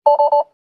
warn.wav